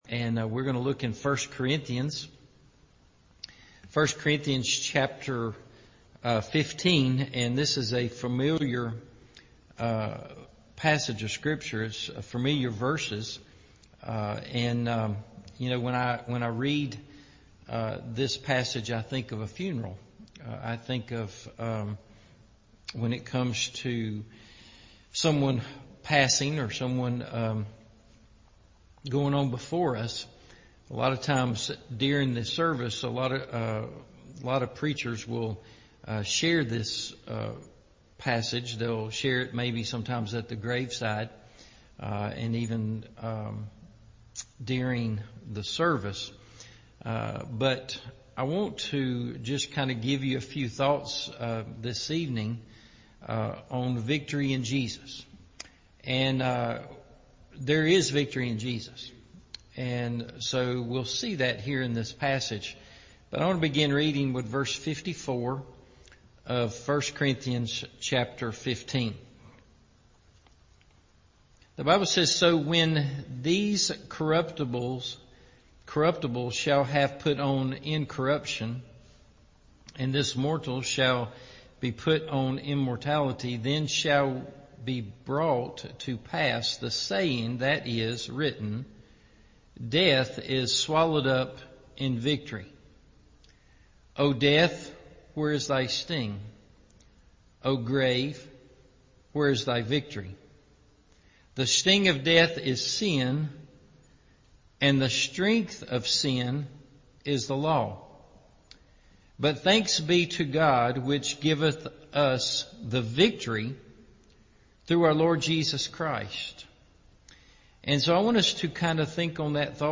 Victory In Jesus – Evening Service